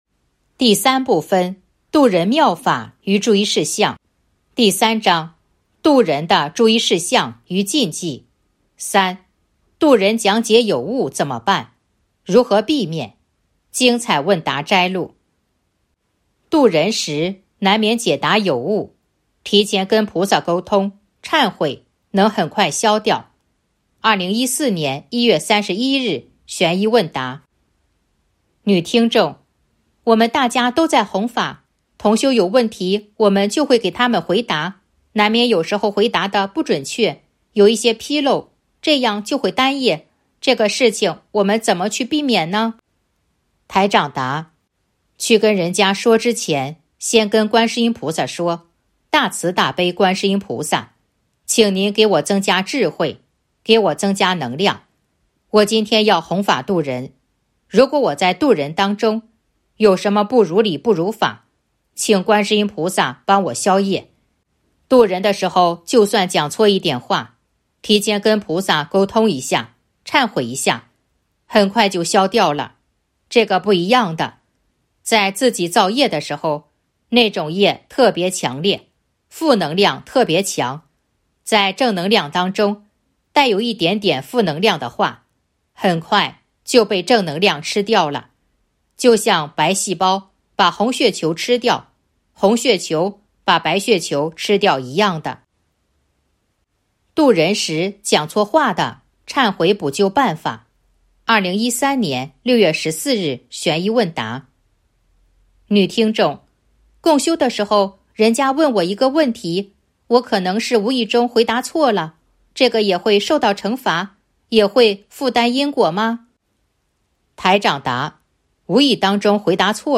055.精彩问答摘录《弘法度人手册》【有声书】